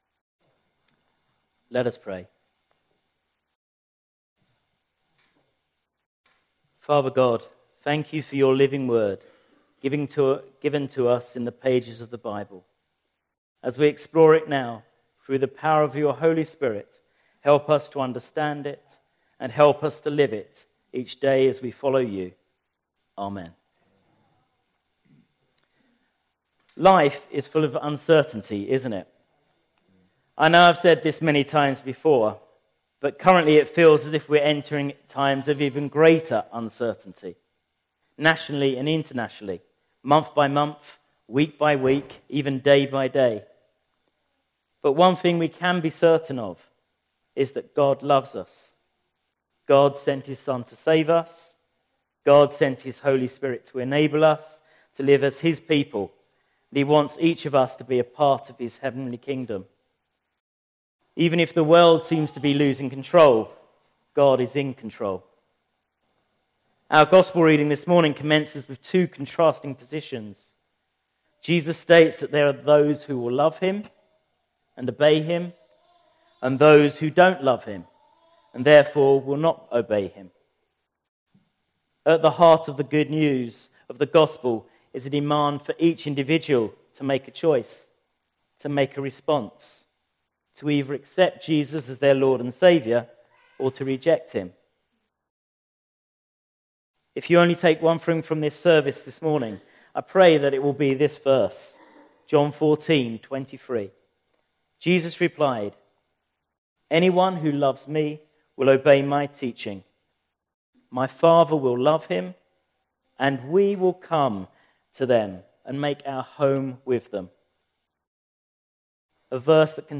A service of morning worship in a contemporary style
Revelation 21:10,22-25 Service Type: Sunday Morning A service of morning worship in a contemporary style